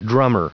Prononciation du mot drummer en anglais (fichier audio)
Prononciation du mot : drummer